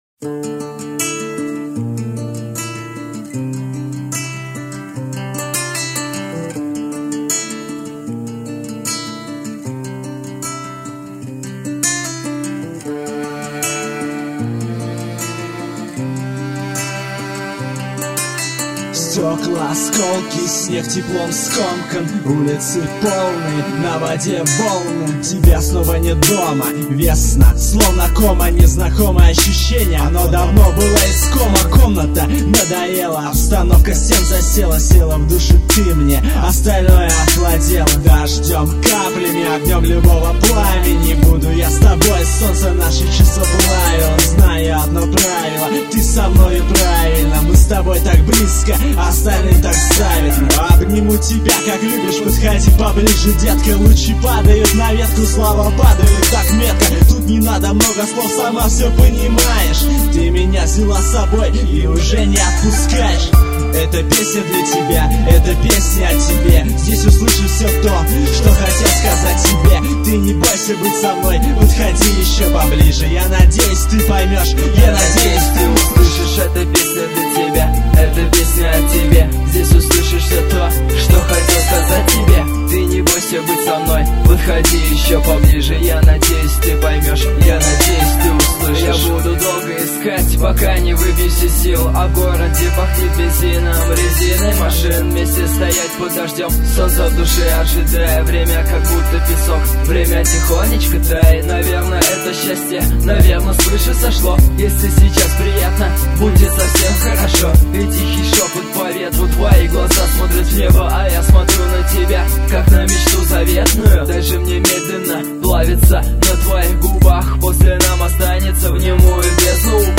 mp3,3793k] Рэп